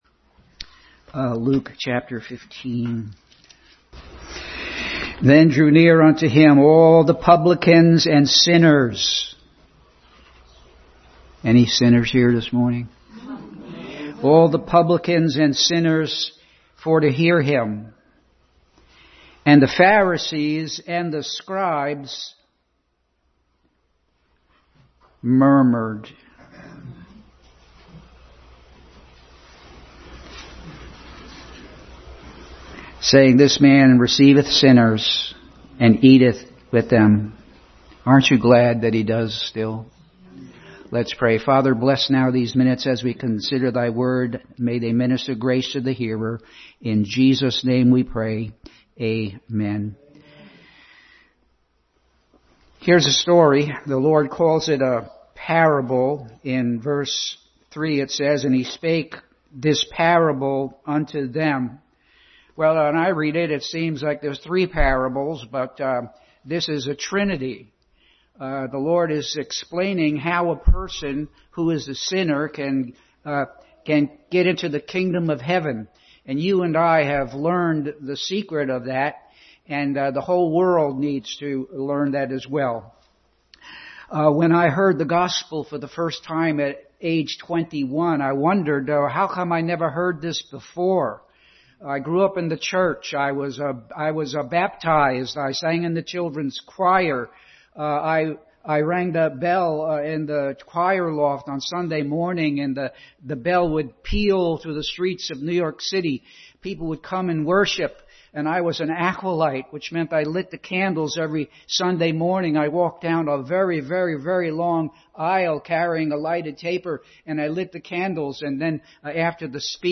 Lost and Found Passage: Luke 15:1-32 Service Type: Sunday School